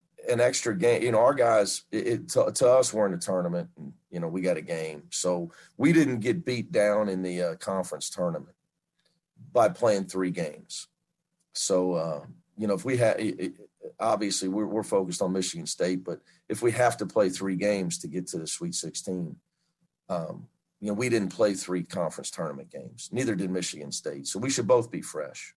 Head Coach Mick Cronin spoke with the media about how his team is approaching the play-in game.